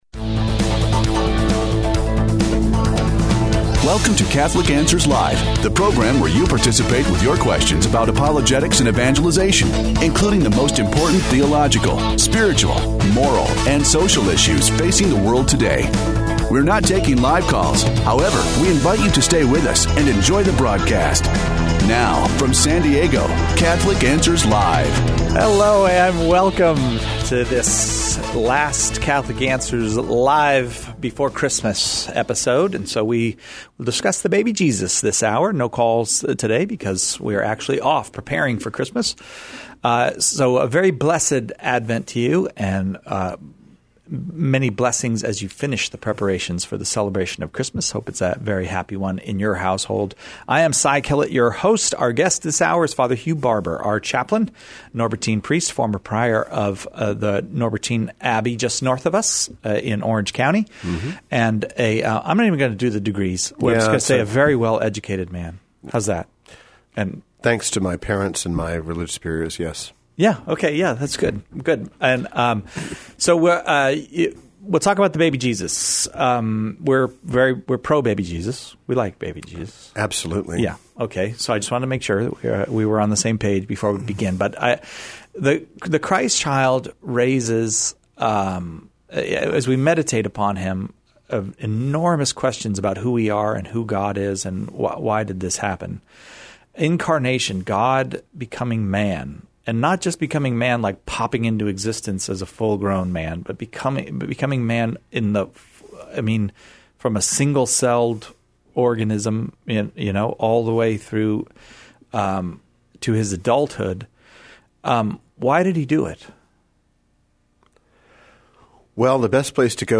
The Baby Jesus (Pre-recorded)